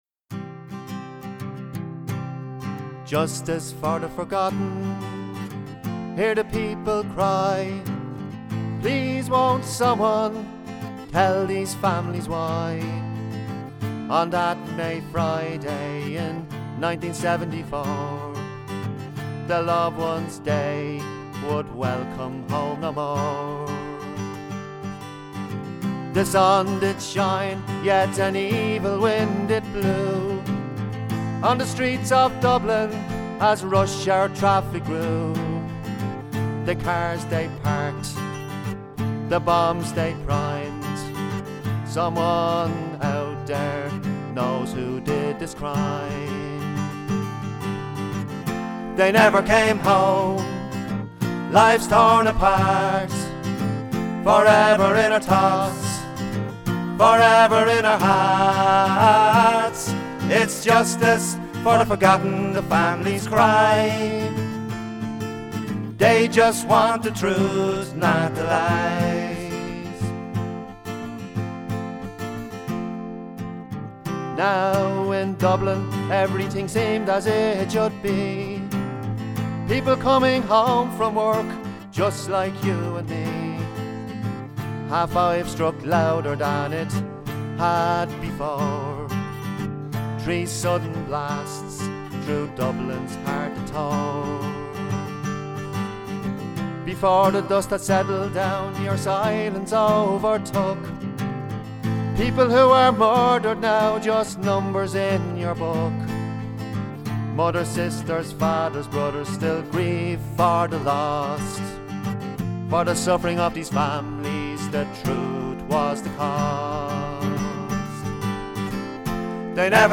Song - Justice For The Forgotten
on the occasion of the 40th anniversary of the Dublin and Monaghan Bombings commemoration events in Monaghan in 2014